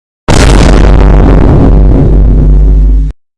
Vine Boom Bass Boost Sound Effect